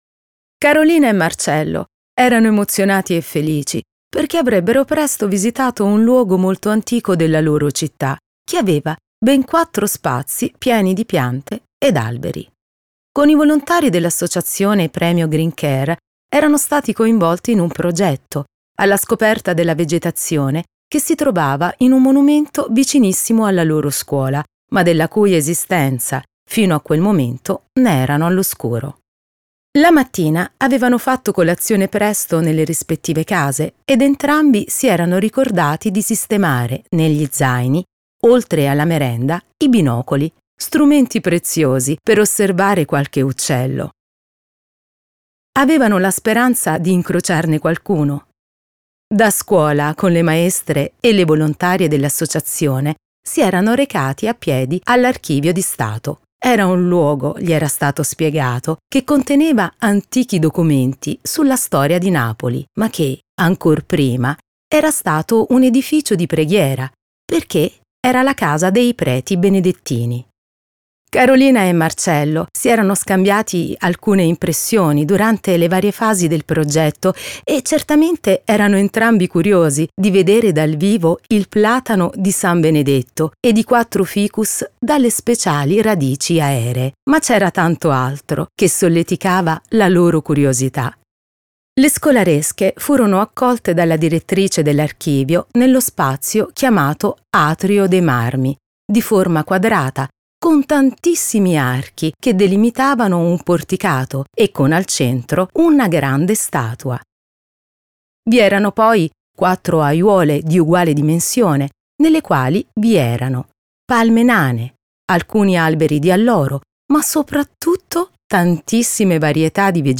Audiolibro GreenCare School Ottava Edizione